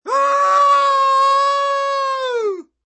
Descarga de Sonidos mp3 Gratis: grito 20.